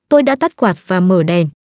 text-to-speech vietnamese